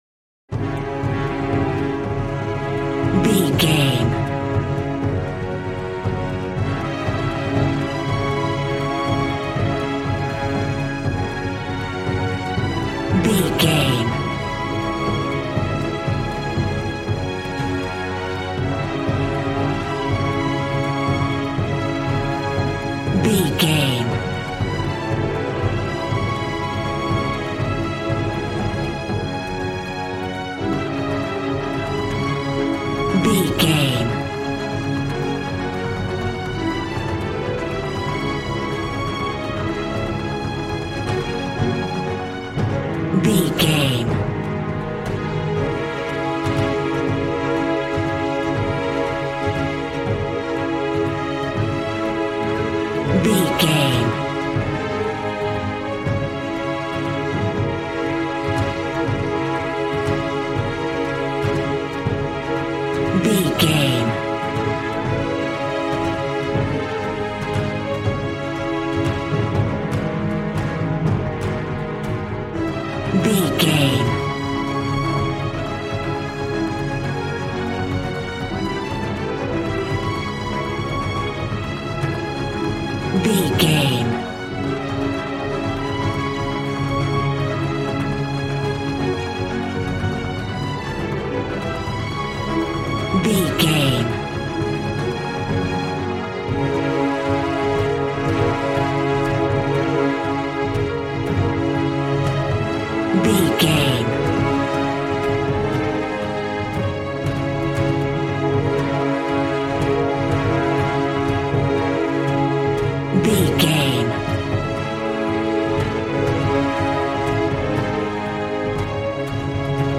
Aeolian/Minor
D♭
suspense
piano
synthesiser